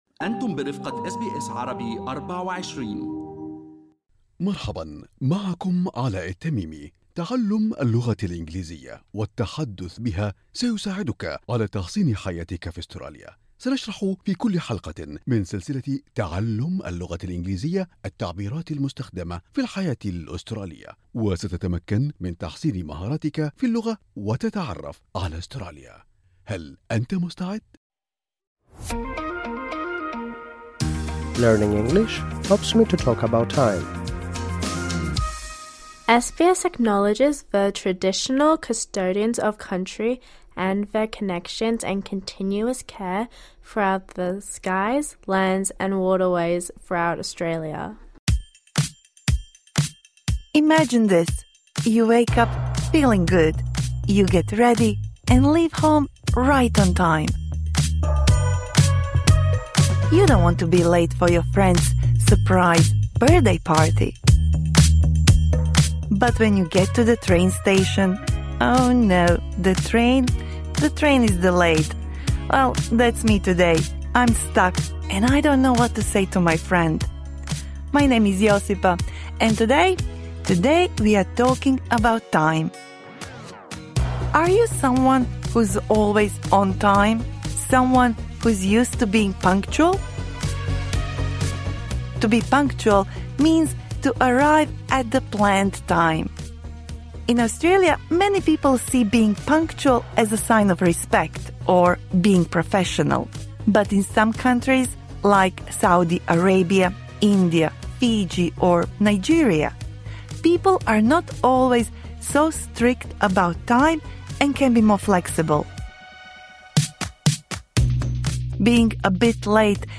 هذا الدرس مفيد للمتعلمين من المستوى المتوسط.